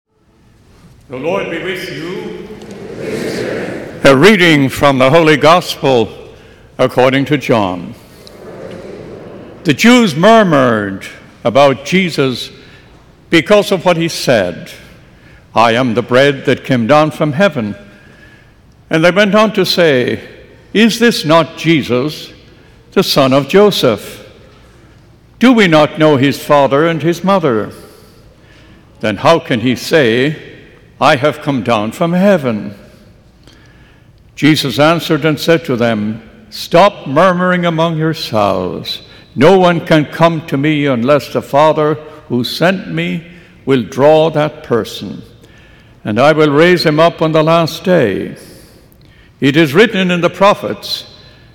Nineteenth Sunday in Ordinary Time, August 11, 2024, 9:30 Mass